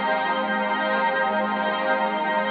lightloop.wav